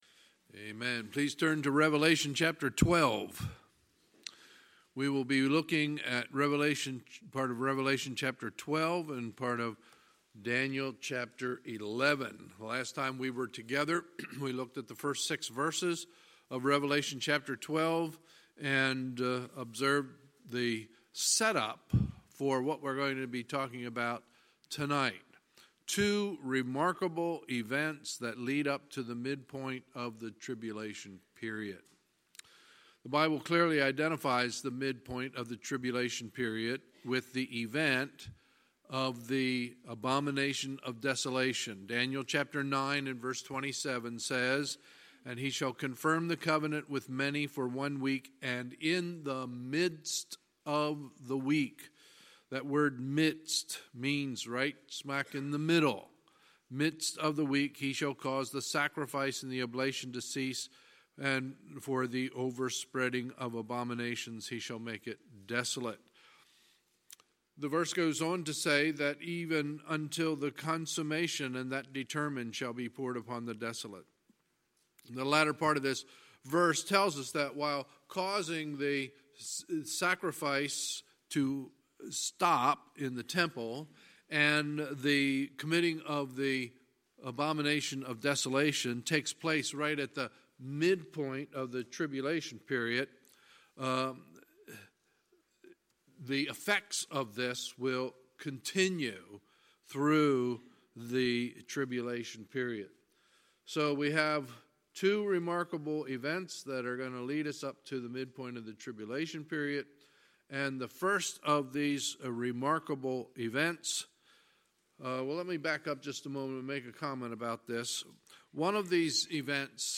Sunday, March 24, 2019 – Sunday Evening Service